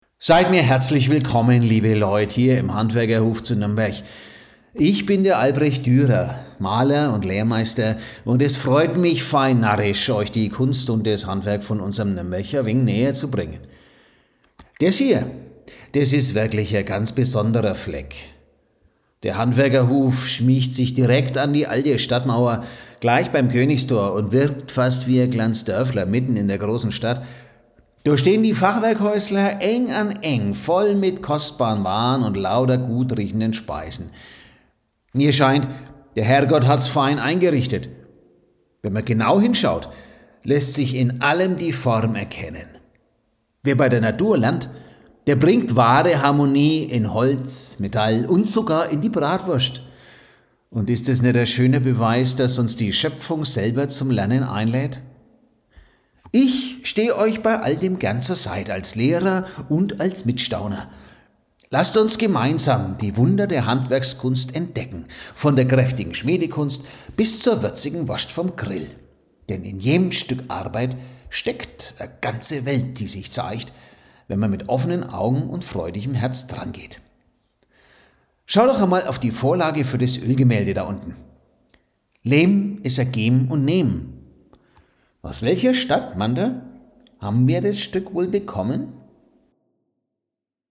1-duerer-mittelalt-souveraener-hall.mp3